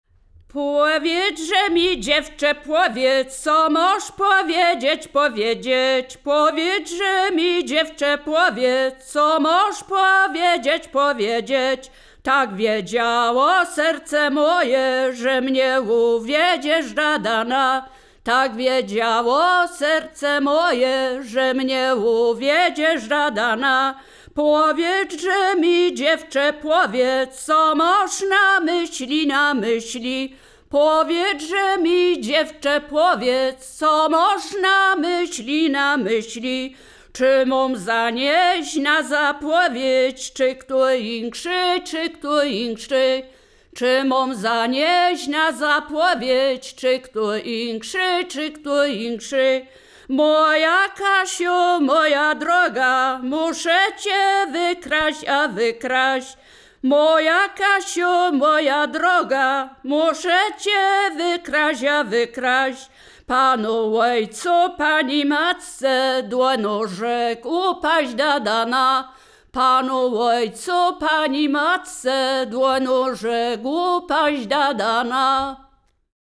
Wielkopolska
liryczne miłosne